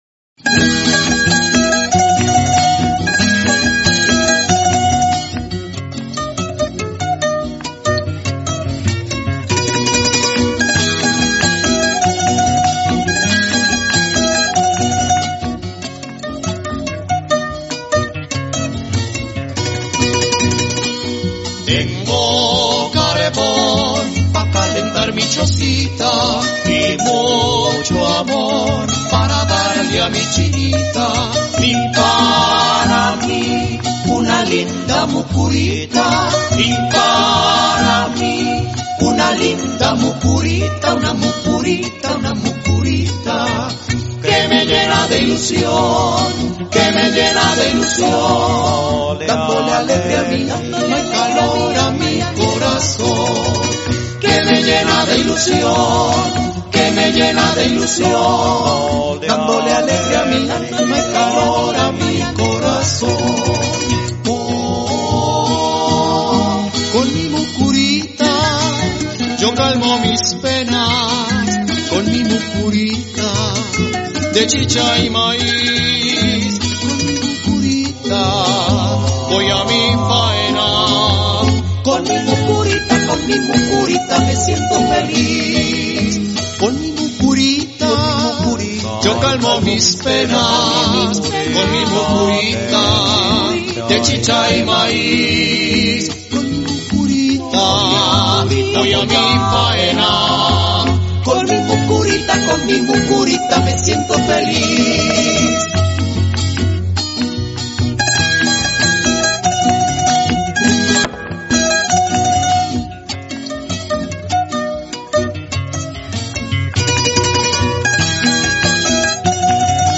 Bambuco Caucano